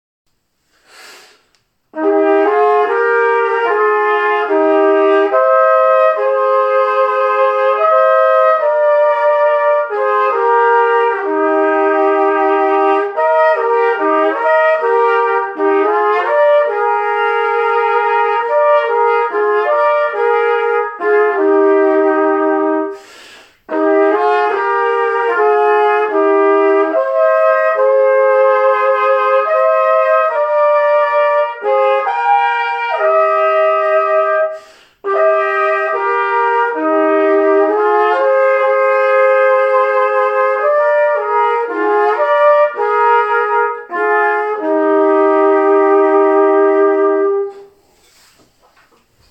Gattung: für 2 Flügelhörner oder Tenorhörner in B
Besetzung: VOLKSMUSIK Weisenbläser